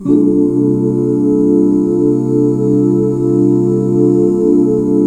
CSUS13 OOO-R.wav